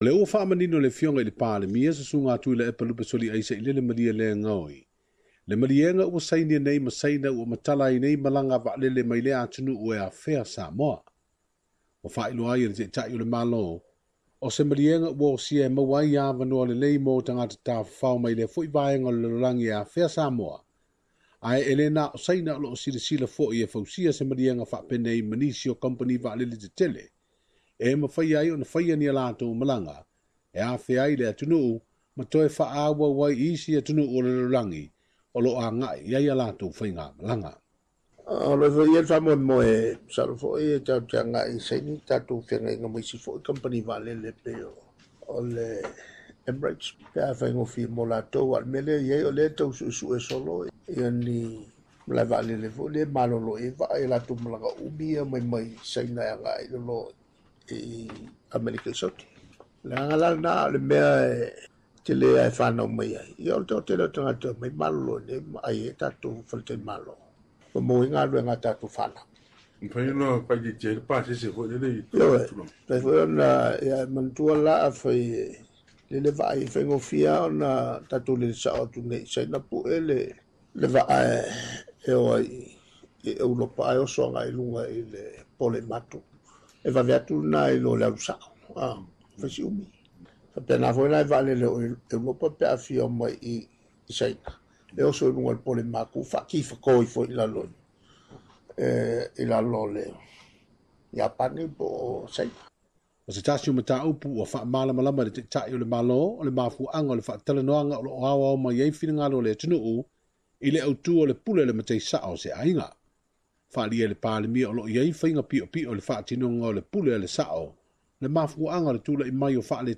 Faamata ua sosopo le malo Samoa i aia tatau a tagata i lalo o tu ma aganuu faaSamoa? Se lipoti